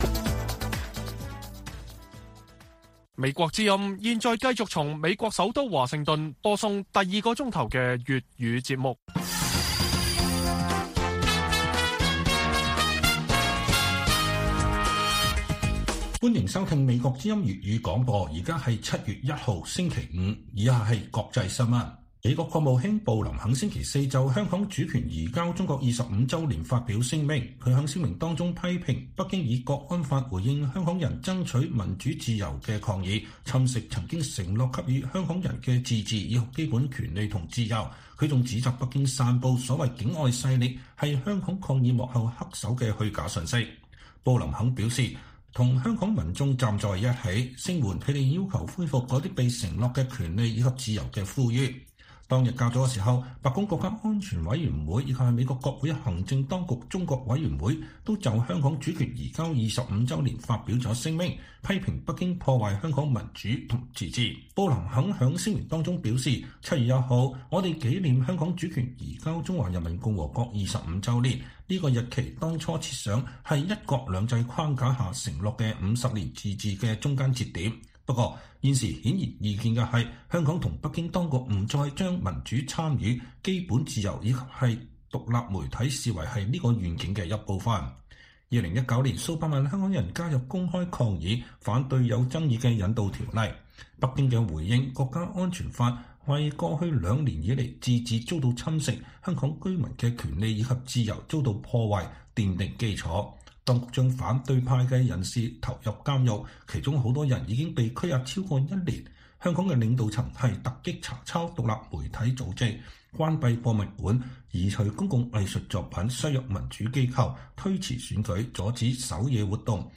粵語新聞 晚上10-11點: 英外交大臣警告：北京可能步普京後塵作出“災難性”戰略誤判